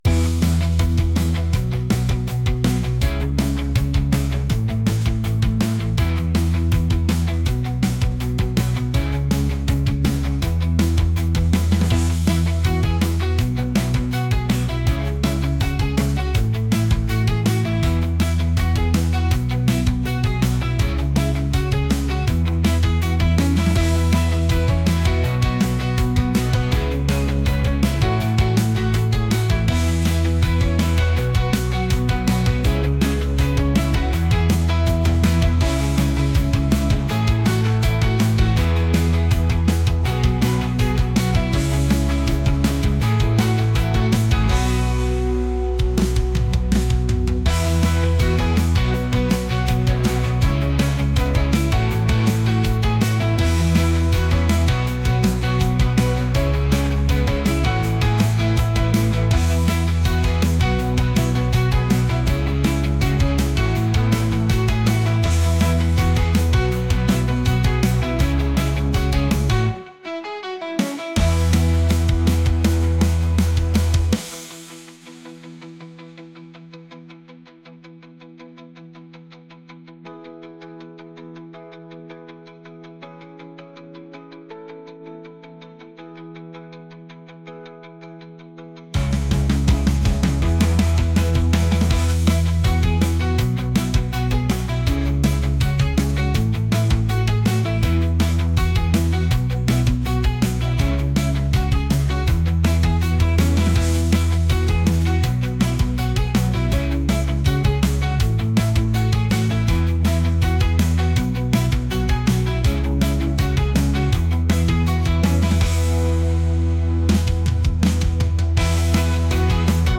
pop | rock | indie